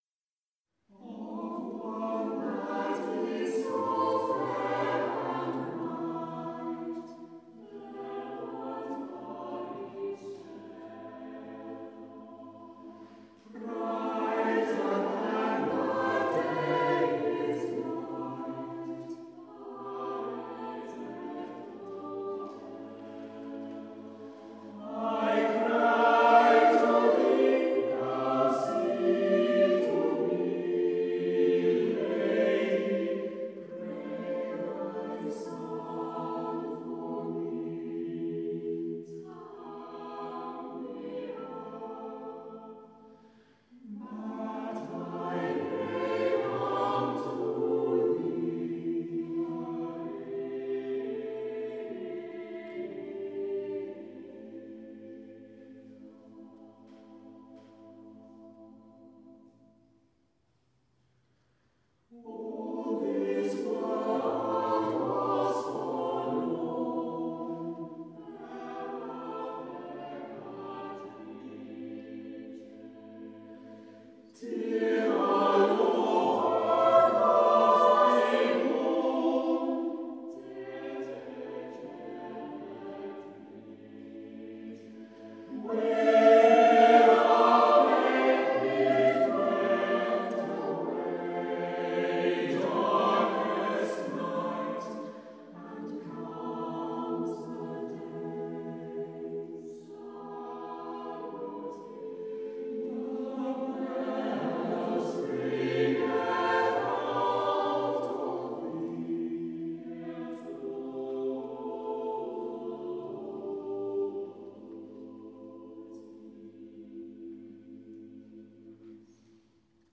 The Choir of Boxgrove Priory
with The Boxgrove Consort of Viols
Recorded live in Boxgrove Priory on the evening of 25th June 2013